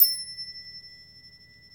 Index of /90_sSampleCDs/Roland - Rhythm Section/PRC_Asian 2/PRC_Windchimes